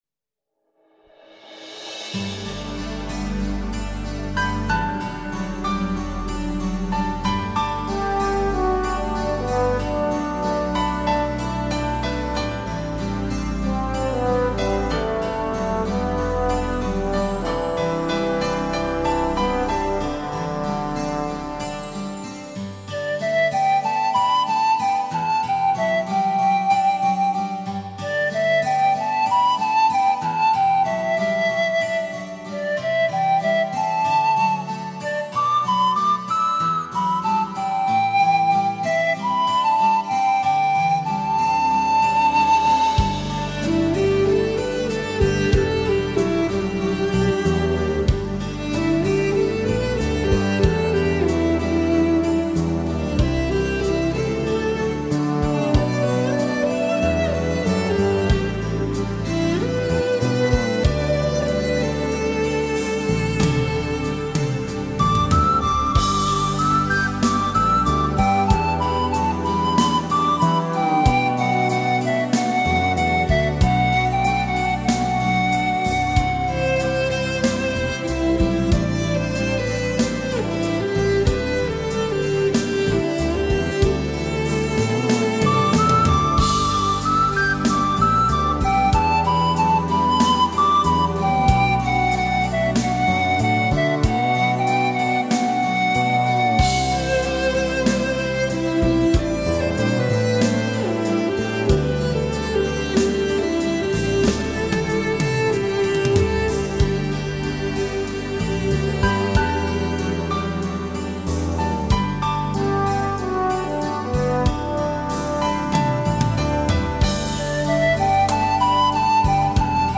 在排箫与大提琴的合奏下，这首深情款款又不 乏轻快乐调的曲子显得更容易打动人心。
其余的曲目都充满着法国式或拉丁式的浪漫与轻盈， 正如同专辑充满诗意的标题一样，把人们引领到了一个超凡脱俗的爱情境界。